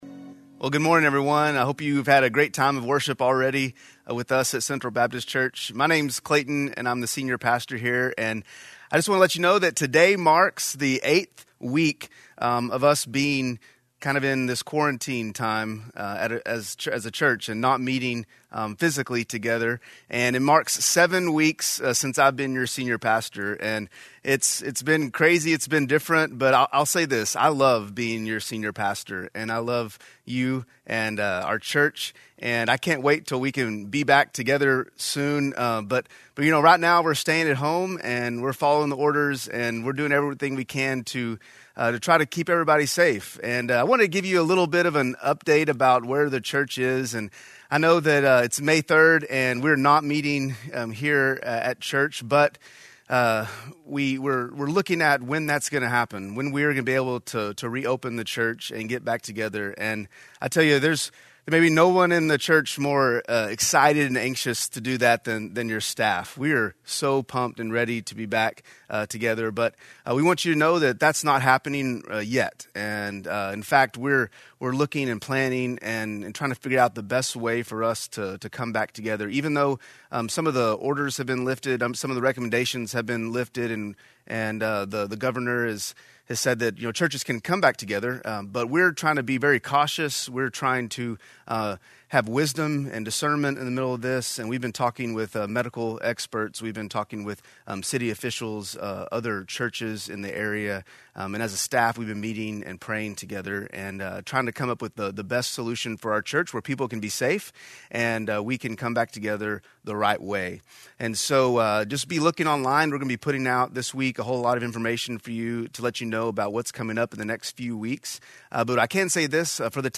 A message from the series "Better Together." When we "Love People" we have community together.